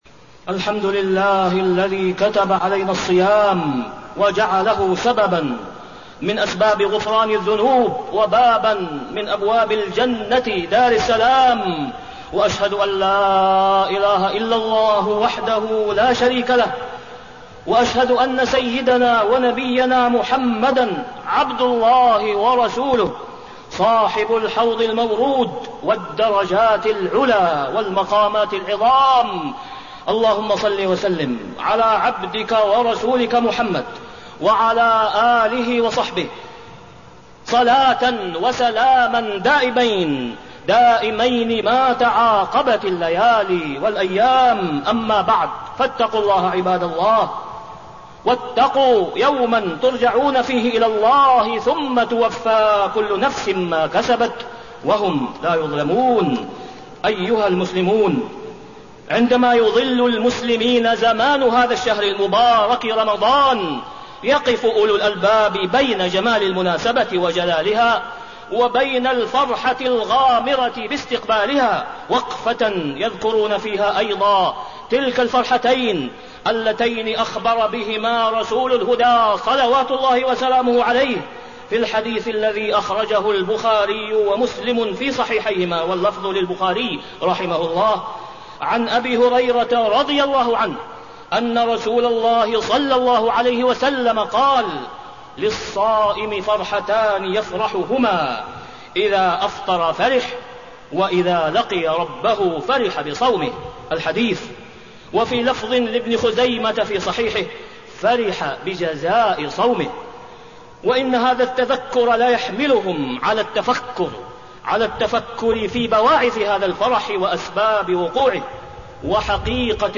تاريخ النشر ٣٠ شعبان ١٤٣٠ هـ المكان: المسجد الحرام الشيخ: فضيلة الشيخ د. أسامة بن عبدالله خياط فضيلة الشيخ د. أسامة بن عبدالله خياط استقبال شهر رمضان